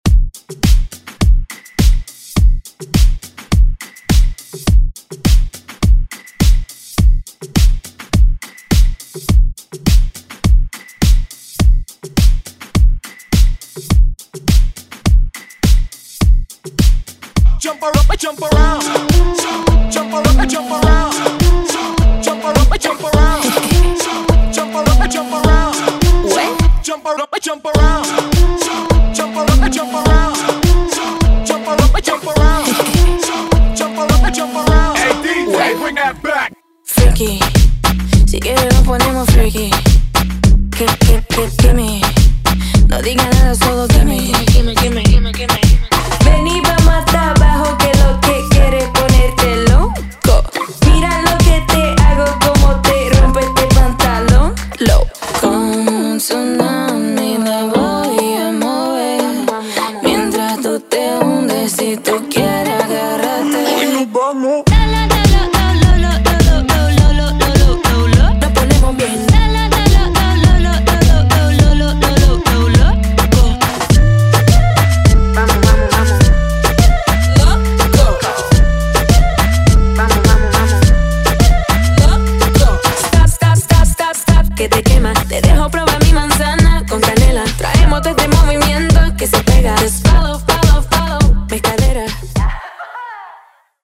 Genre: DANCE
Clean BPM: 126 Time